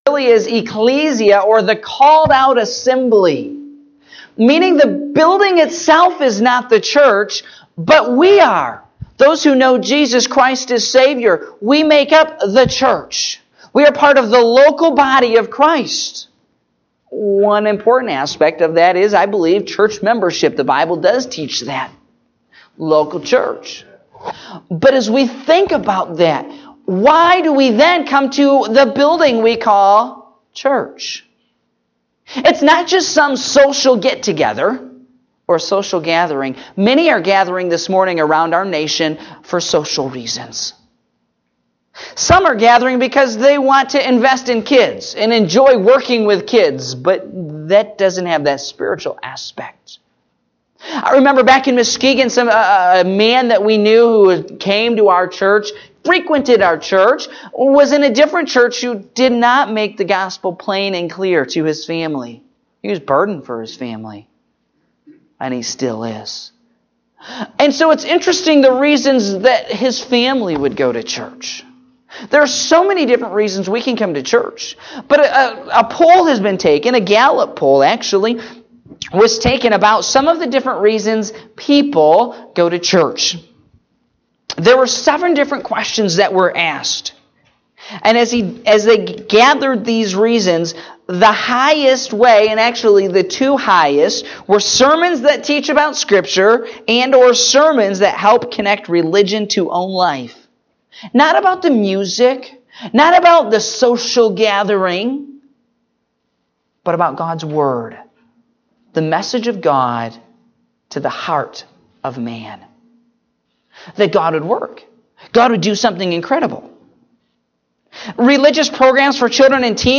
Morning Service (10/15/2017)